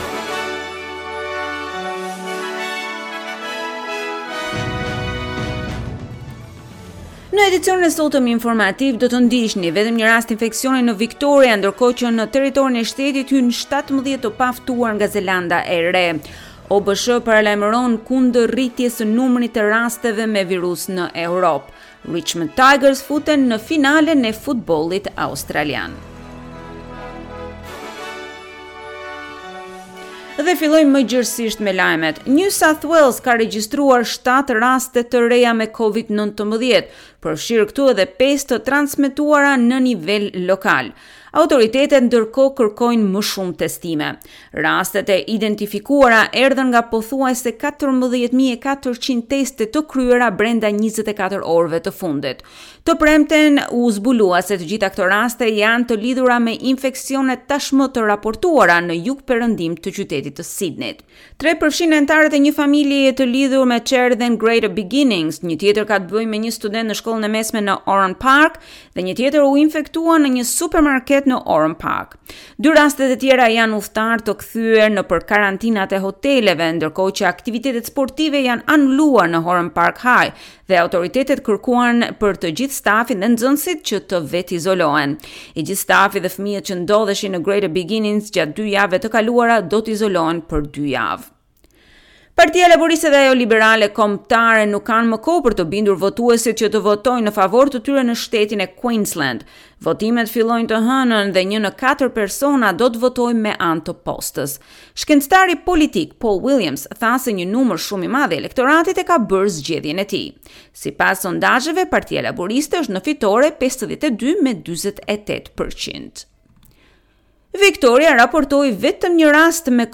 SBS News Bulletin - 17 October 2020